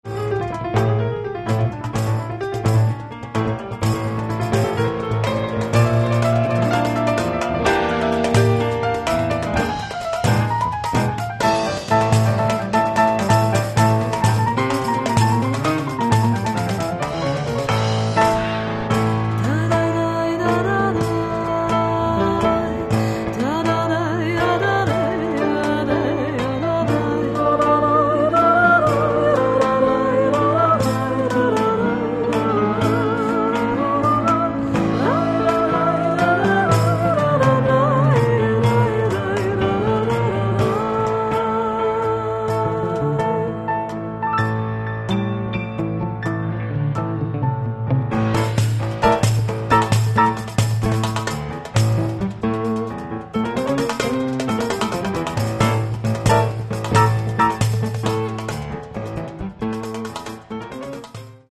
Каталог -> Джаз и около -> Этно-джаз и фольк